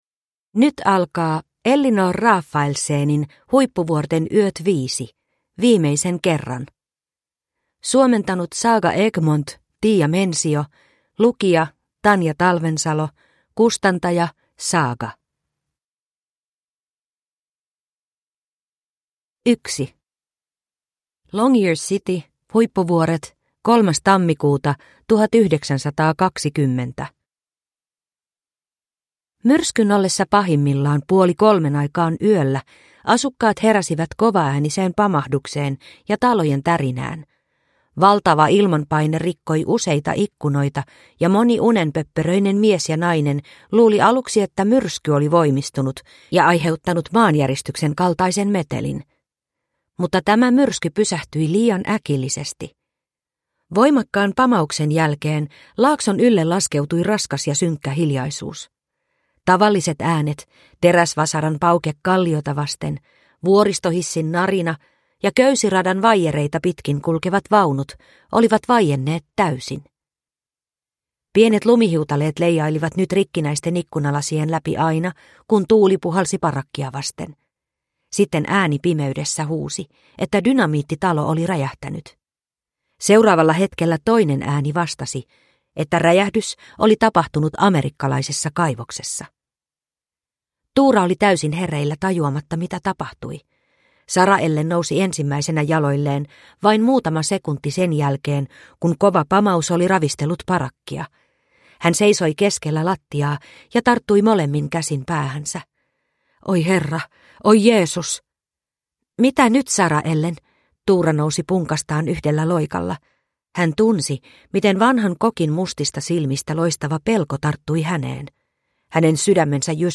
Viimeisen kerran - Huippuvuorten yöt 5 – Ljudbok